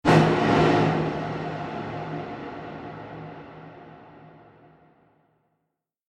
دانلود صدای بمب 5 از ساعد نیوز با لینک مستقیم و کیفیت بالا
جلوه های صوتی